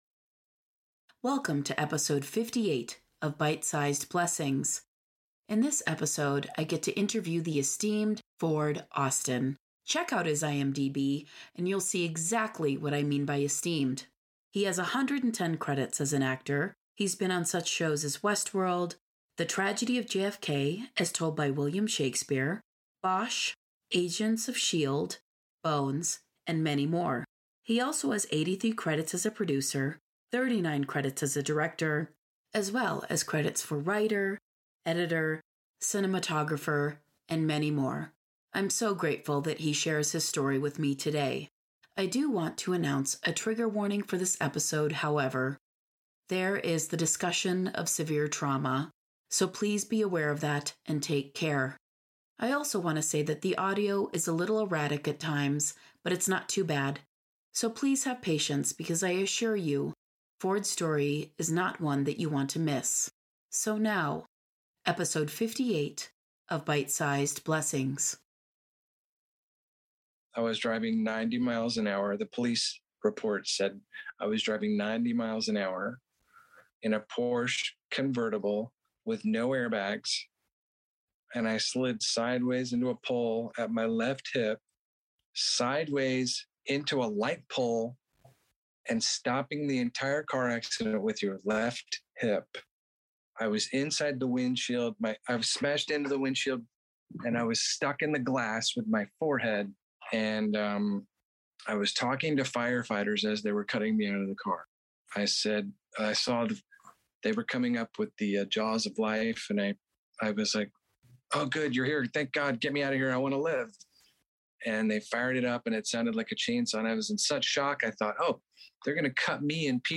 at least that what it felt like during our Zoom interview